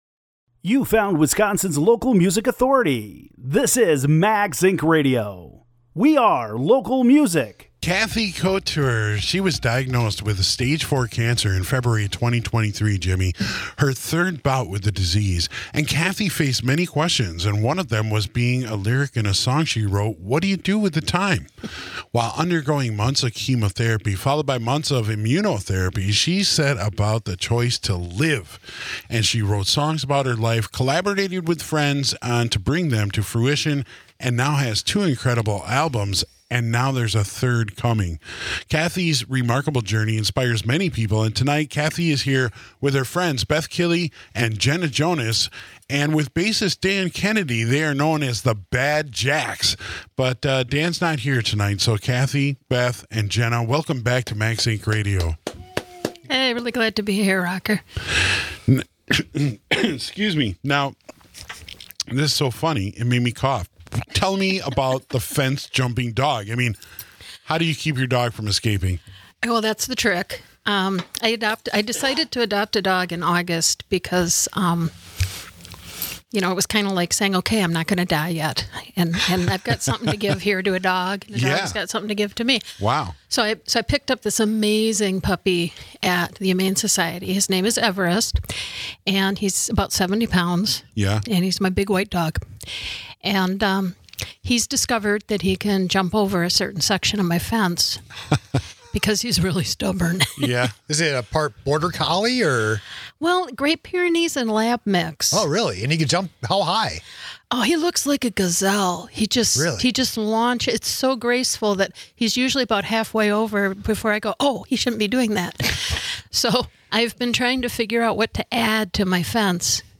interview plus performance of a few songs from her catalog and new album
guitar
percussion